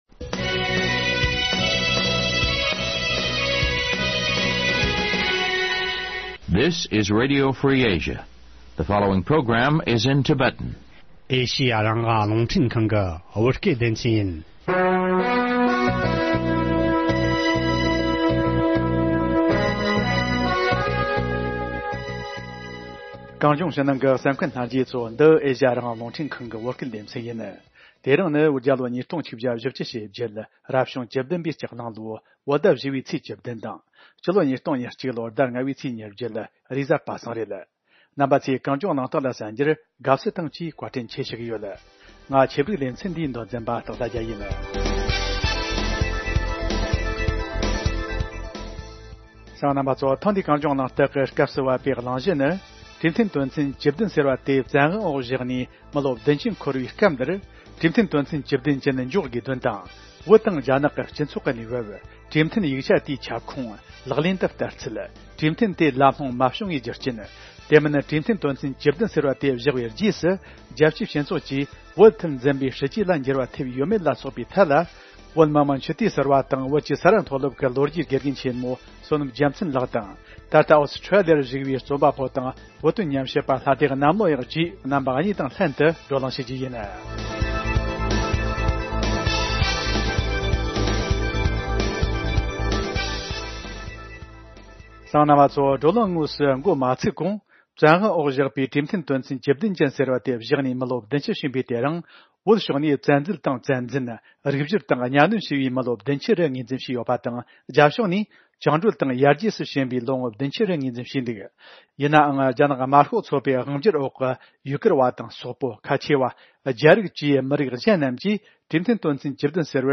༄༅།།ཐེངས་འདིའི་གངས་ལྗོངས་གླེང་སྟེགས་ཀྱི་སྐབས་སུ་བབས་པའི་གླེང་བྱ་ནི།